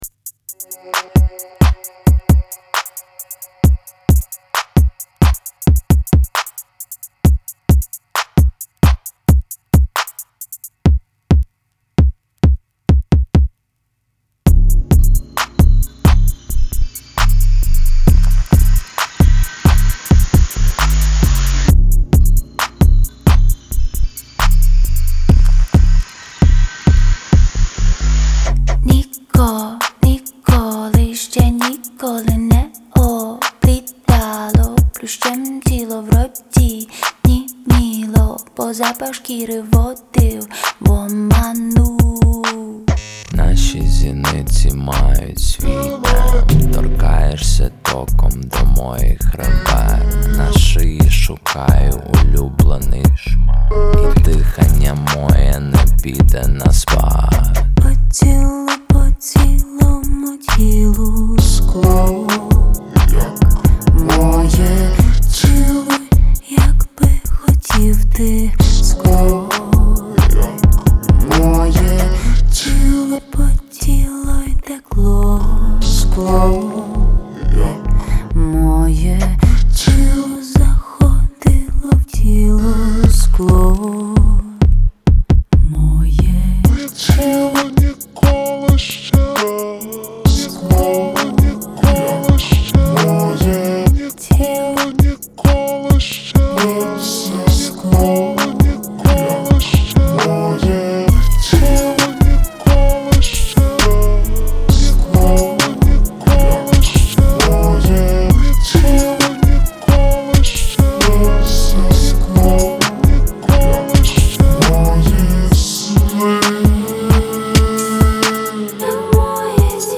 • Жанр: Soul